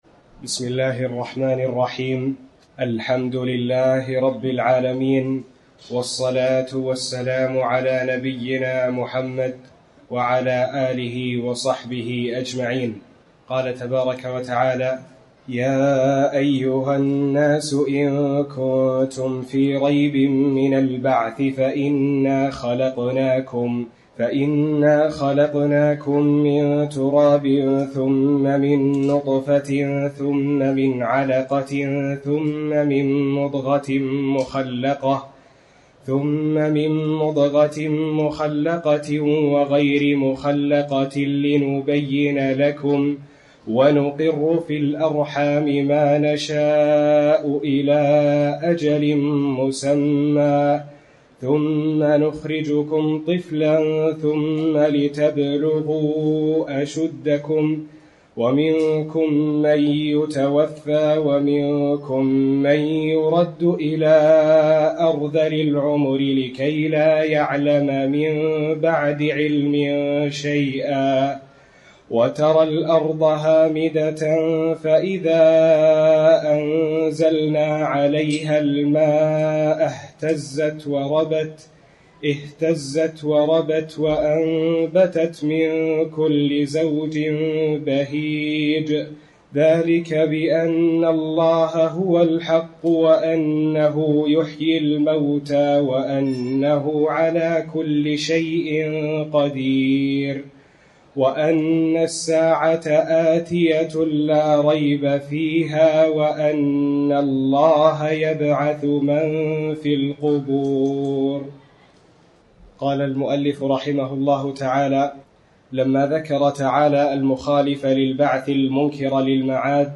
تاريخ النشر ٢٥ شوال ١٤٤٠ هـ المكان: المسجد الحرام الشيخ